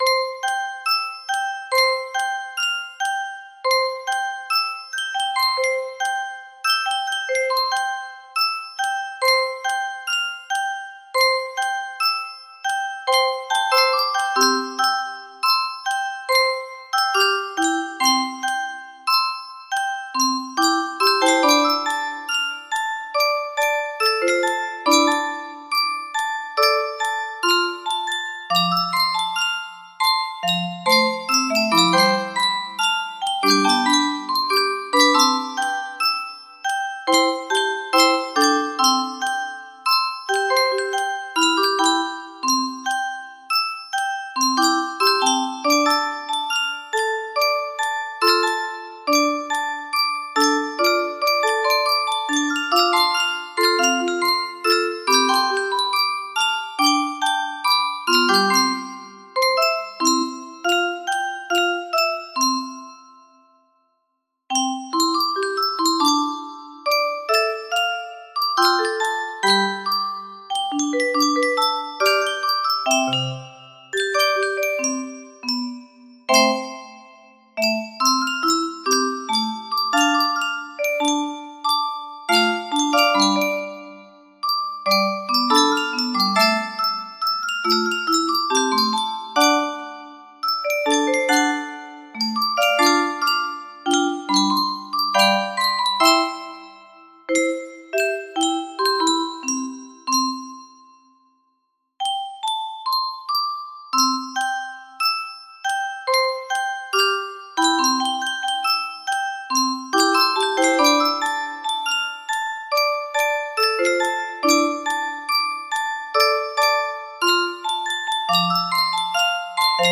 Full range 60
This is an Arrangement I created Today Of It!